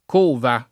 cova [ k 1 va ] s. f.